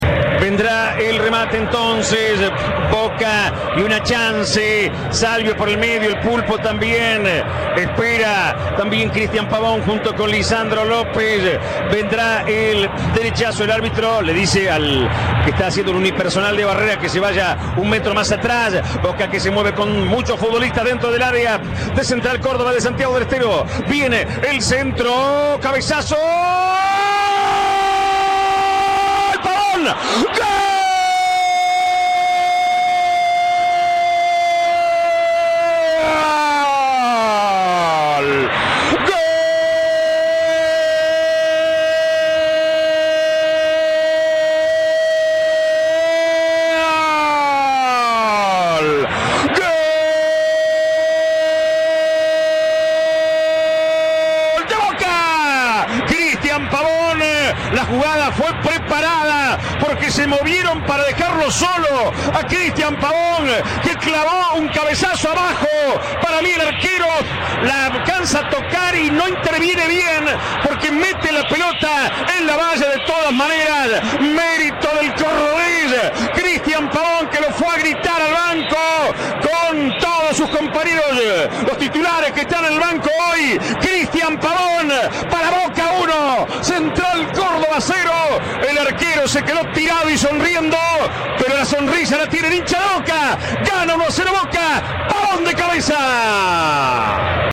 Fiesta en "La Bombonera": reviví el relato de los 8 goles